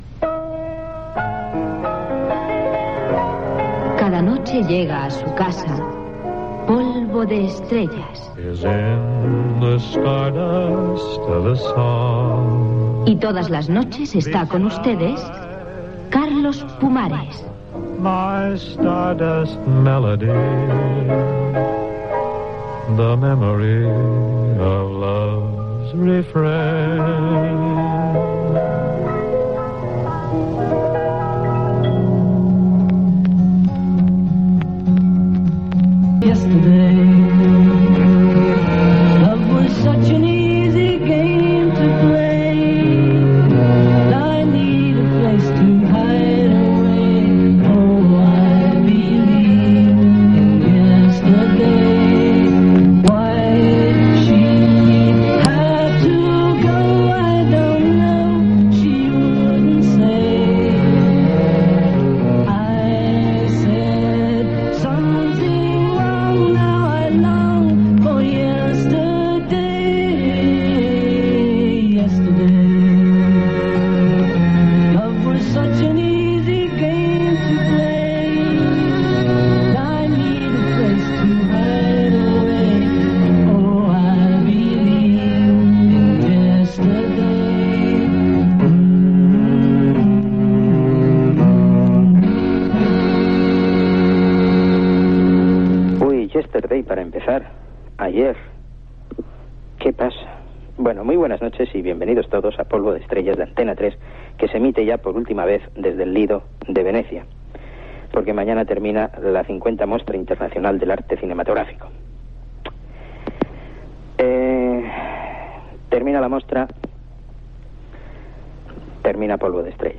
Careta del programa, tema musical, presentació del programa des de la 50è Festival Internacional de Cine de Venecia i anunci que és l'últim programa de nit després d'11 anys i que l'espai canvia d'hora
FM